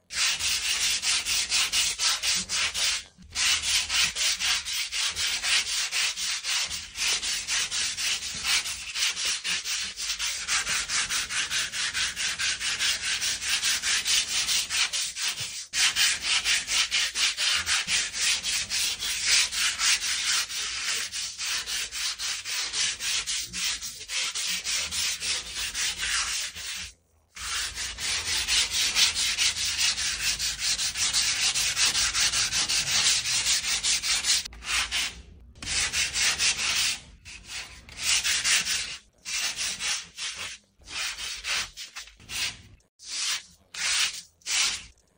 Tiếng Chà giấy Giáp, giấy Nhám, giấy Nháp… bằng tay
Thể loại: Tiếng động
Description: Tiếng chà giấy giáp, giấy nhám, giấy ráp, giấy nháp vang rì rào, rít nhẹ rồi lạo xạo trên bề mặt gỗ, kim loại. Âm thanh cọ xát, mài, xả, miết, đánh bóng tạo nhịp đều, lúc xướt xát, lúc mượt mà.
tieng-cha-giay-giap-giay-nham-giay-nhap-bang-tay-www_tiengdong_com.mp3